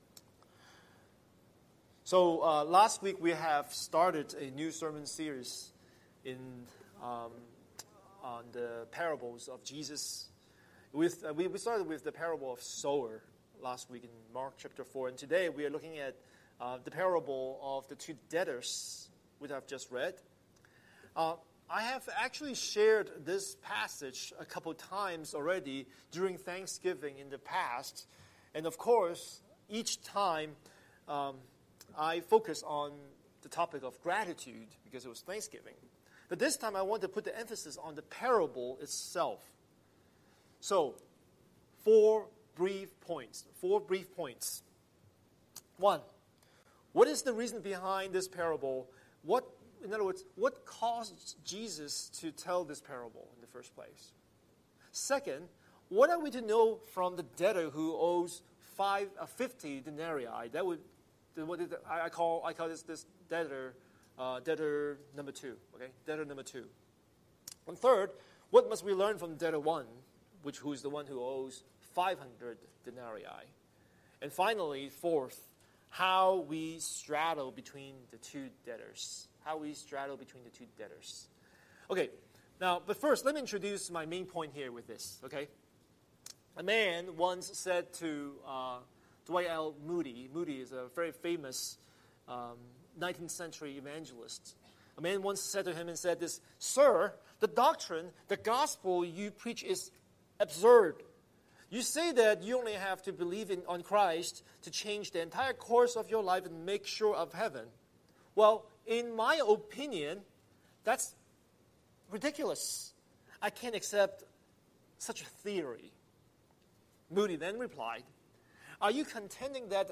Scripture: Luke 7:36–48 Series: Sunday Sermon